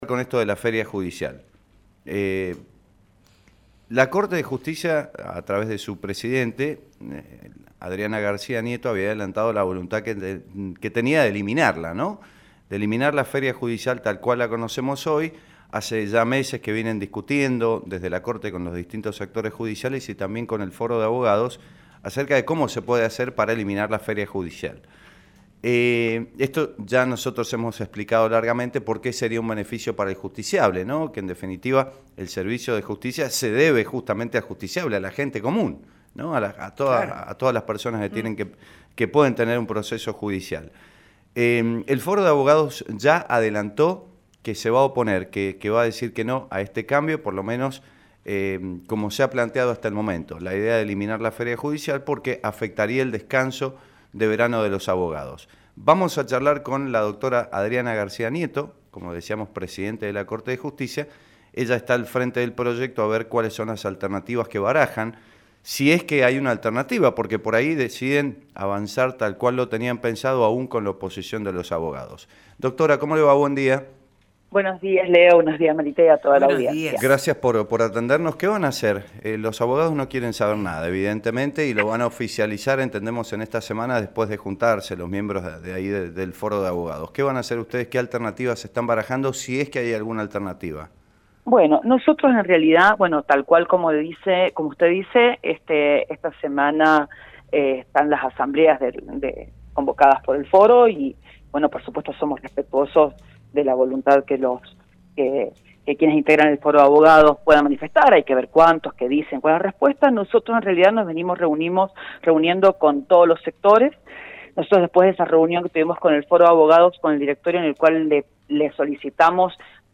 Este lunes, la Presidente de la Corte de Justicia, Adriana García Nieto, hablo , sobre eliminación de la feria judicial y las diferentes alternativas de aplicación ante el posible rechazo del Foro de Abogados.